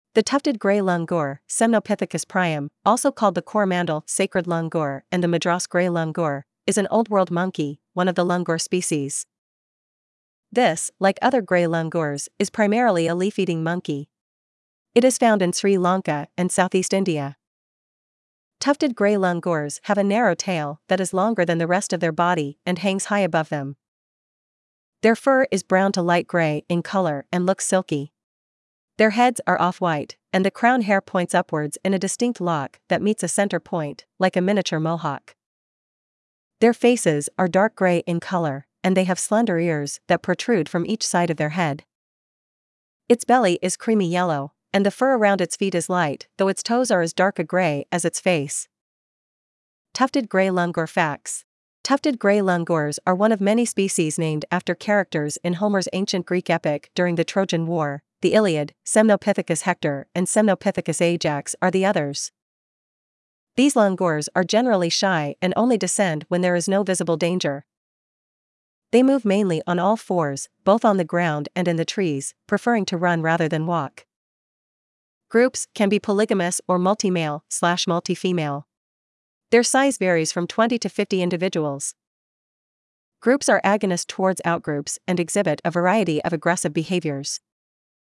Tufted Gray Langur
tufted-gray-langur.mp3